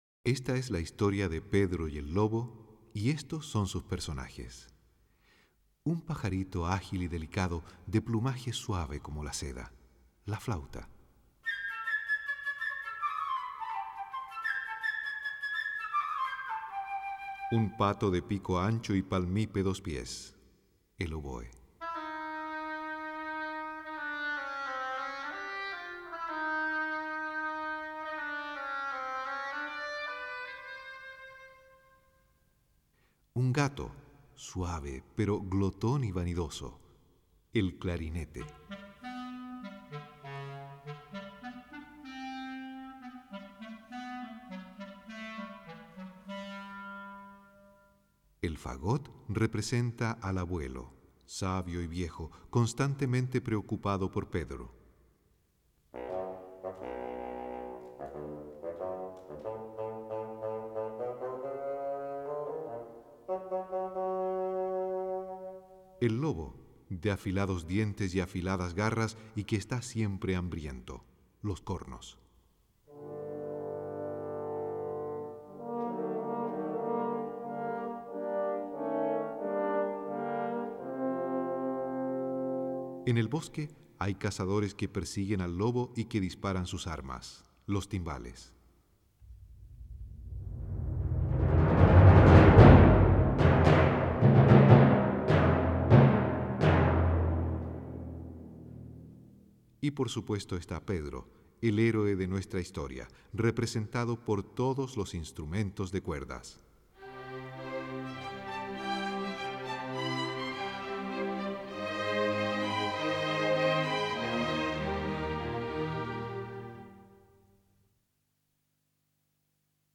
Cuento musical en el que diferentes instrumentos representan a los diferentes personajes